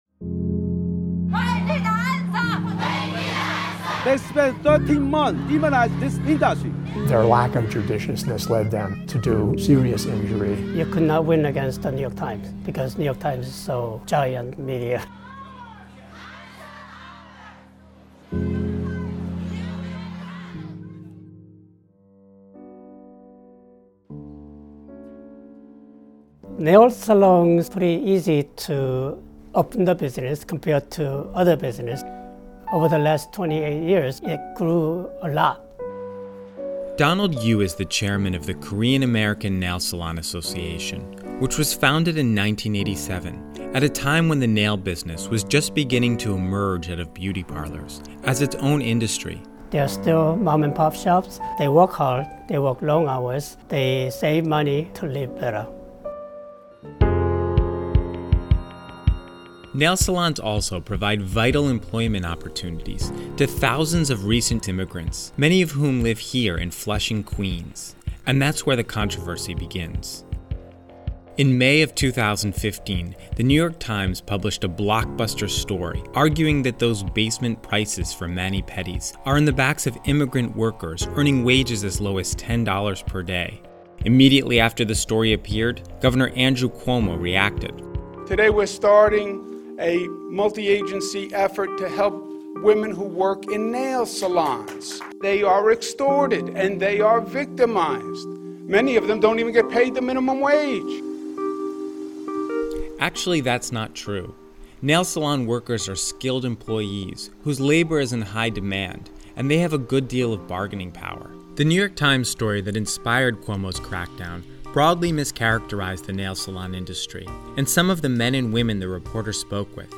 Click above to watch a short documentary on the New York Times attack on the nail salon industry.